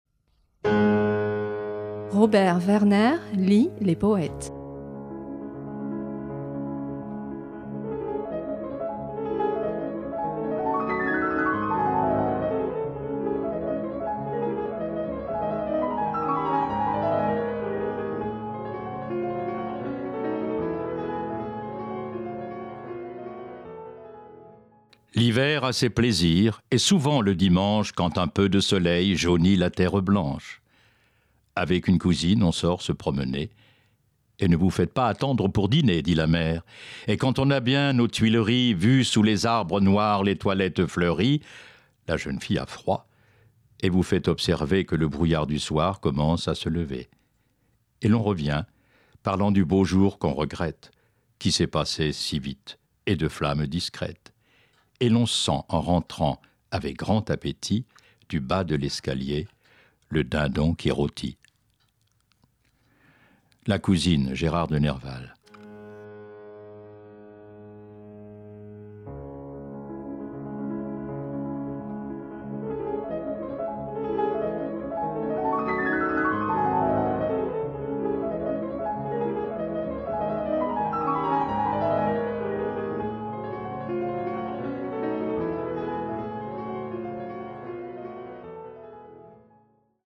À voix lue